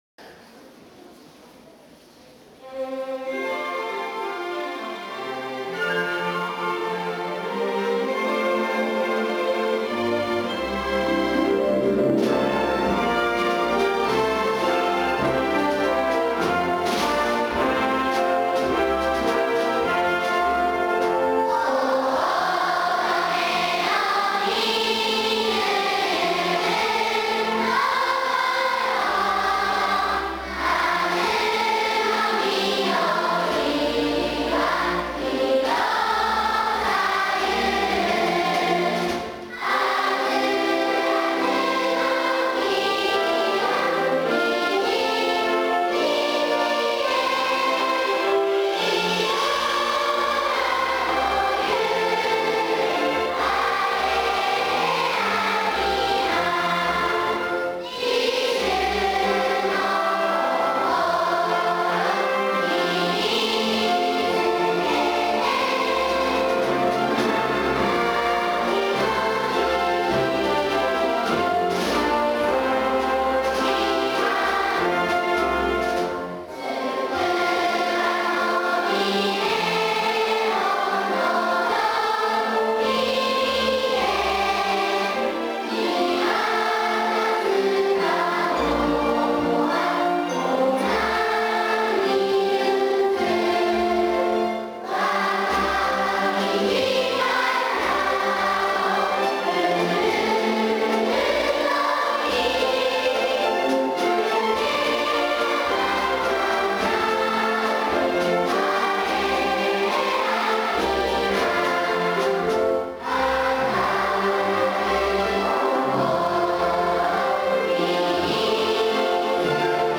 群馬交響楽団オーケストラと全校児童による校歌(音声のみ)です。